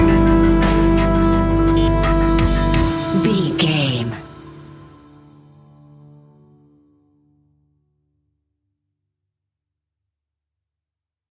Aeolian/Minor
D
ominous
eerie
piano
strings
drums
synthesiser
ticking
electronic music